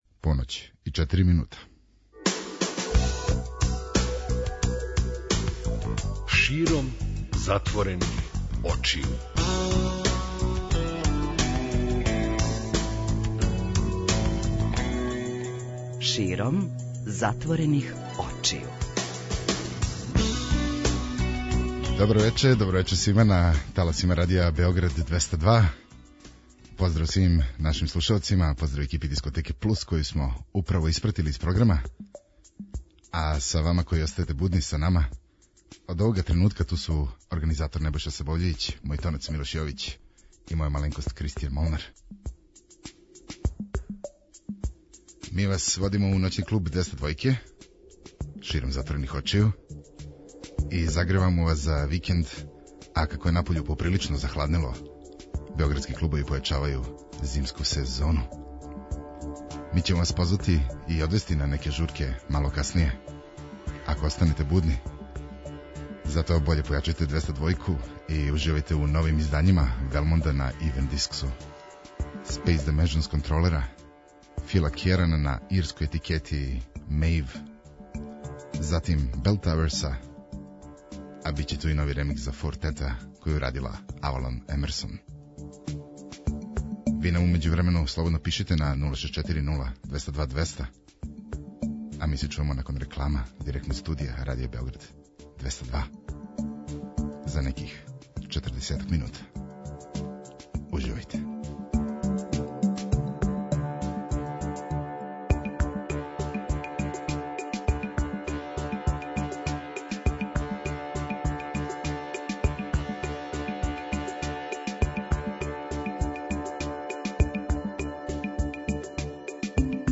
Ди-џеј